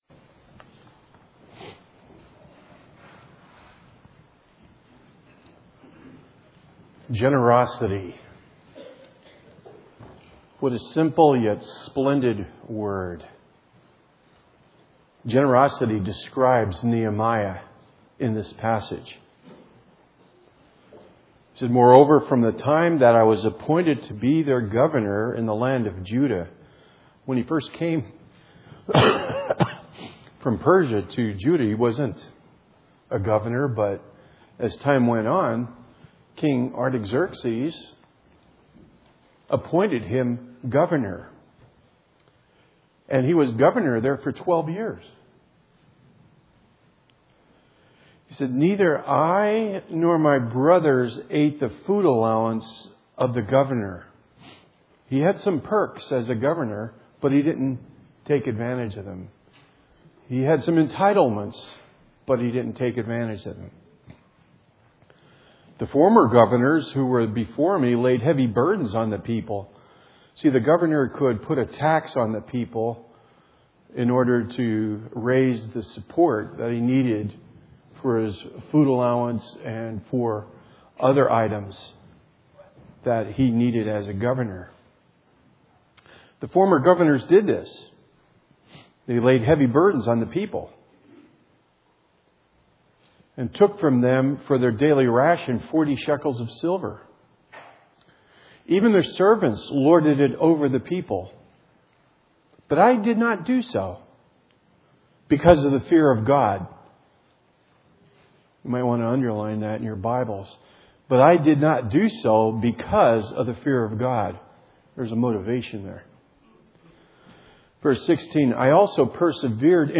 2017 Sermons